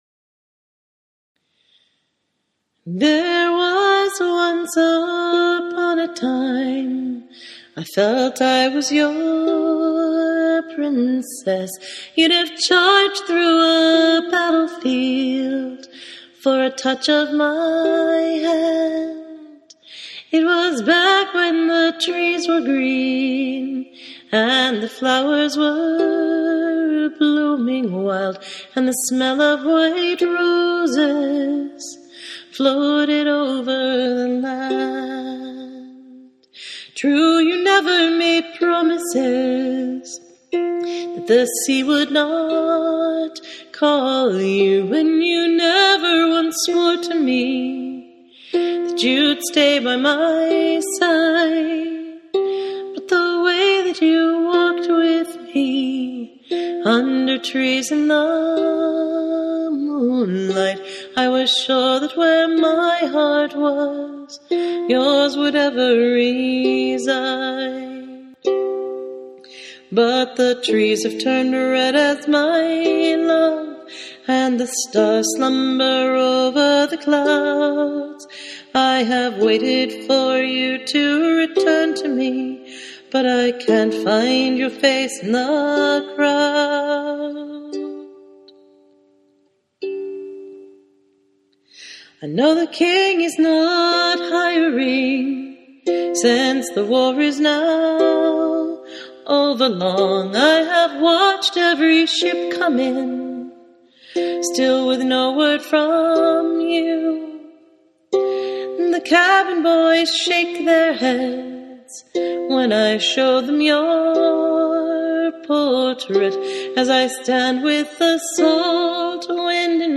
This song came, and I’m glad I let the little melancholic, heartbroken thing in out of the rain.